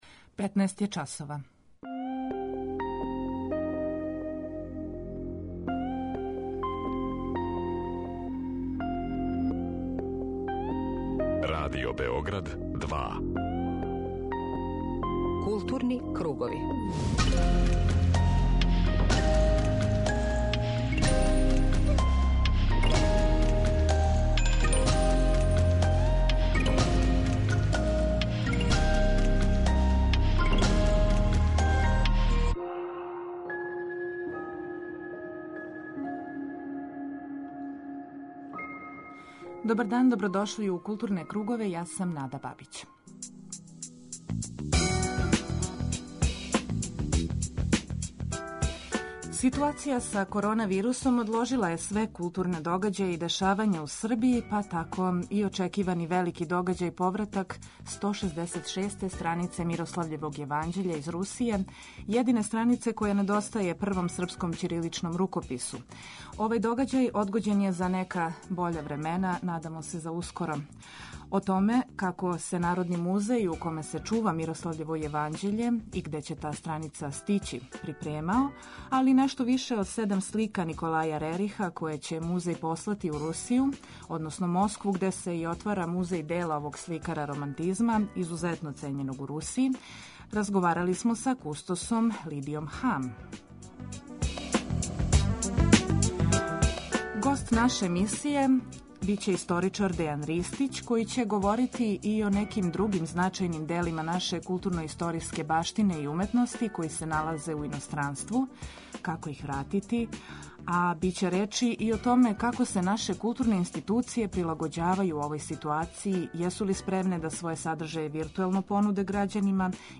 Позивамо и слушаоце да се укључе у програм и кажу своје мишљење о овој теми.
преузми : 19.69 MB Културни кругови Autor: Група аутора Централна културно-уметничка емисија Радио Београда 2.